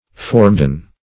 Formedon \For"me*don\ (f[^o]r"m[-e]*d[o^]n), n. [OF., fr. Latin.